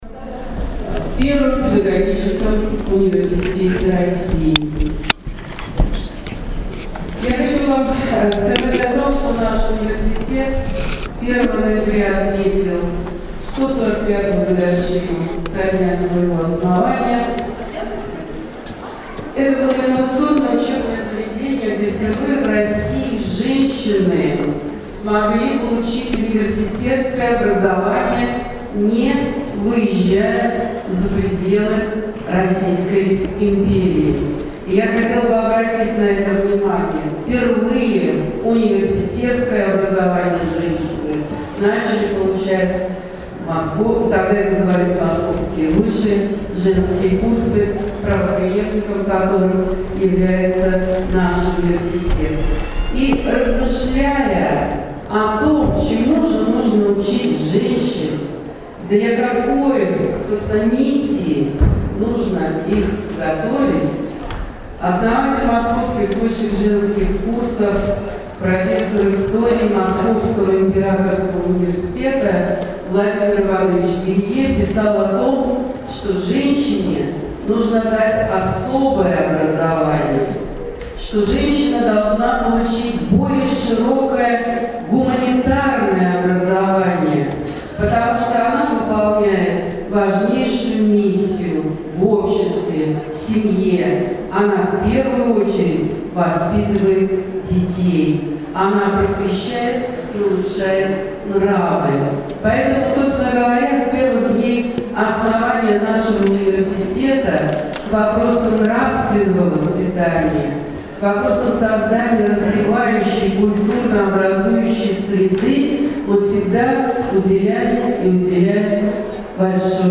30 ноября 2017 года в Москве в главном корпусе Московского педагогического университета состоялась Всероссийская научно-практическая конференция «Читающий учитель».
Аудиозапись приветственного слова